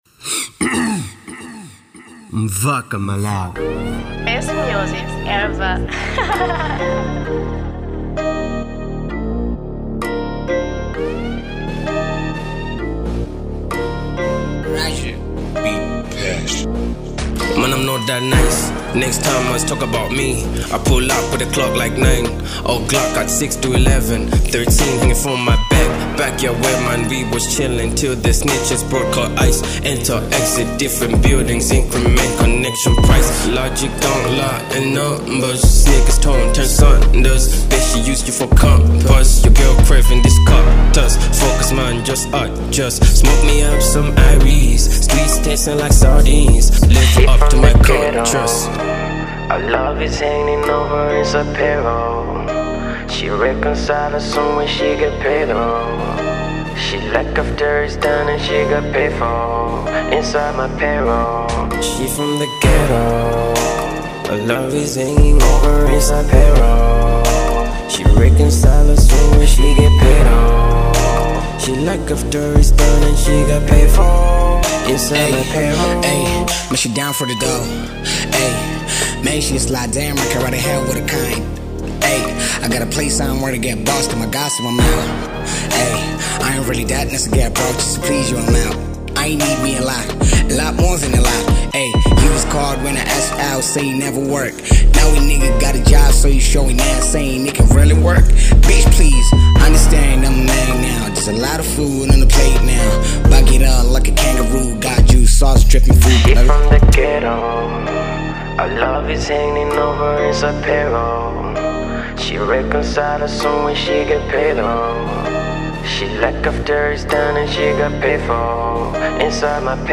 type:Trap